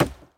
wood_hit.ogg